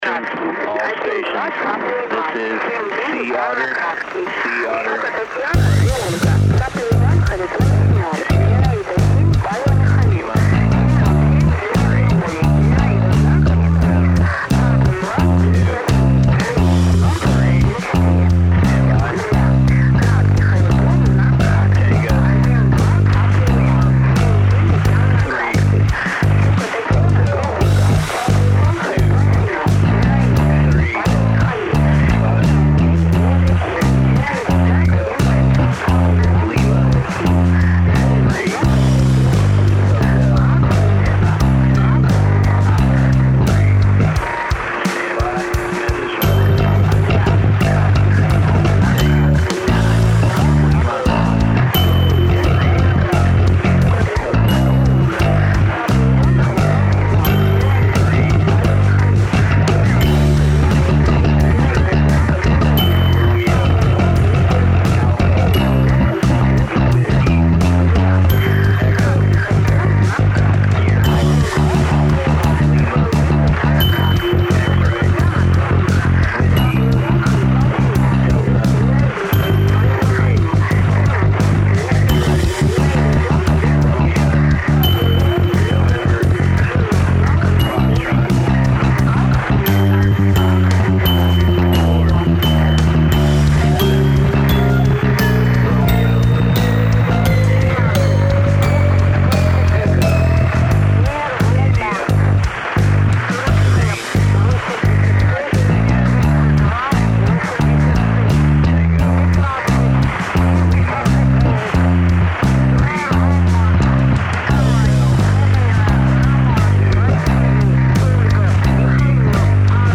Gradual emphasis of repetitions
(No lyrics—Round Zero gamble)
Left channel: American (?) numbers station
Right channel: Russian (?) numbers station